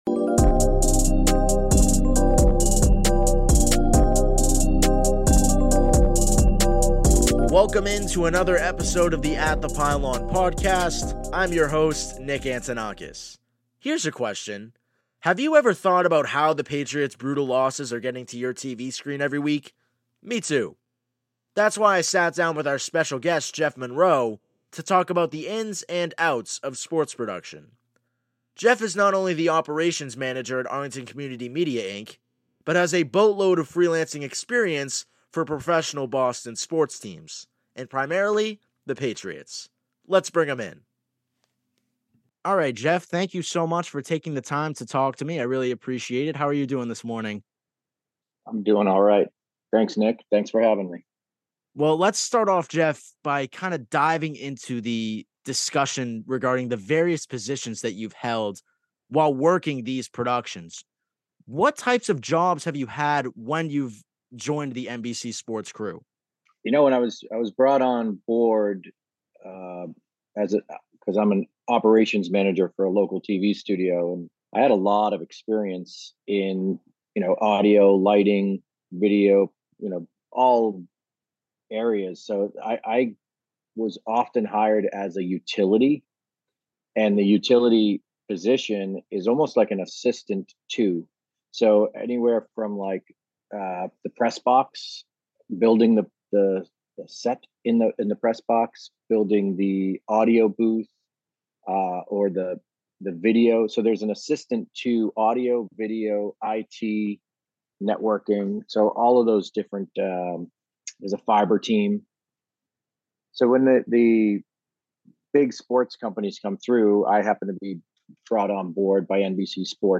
Ad break